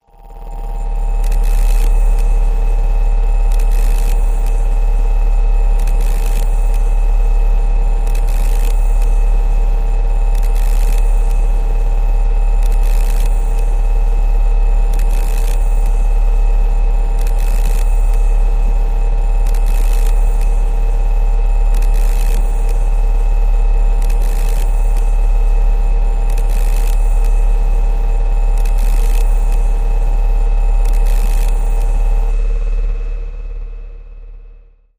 Short Circuit electronic static with underlying low motor tone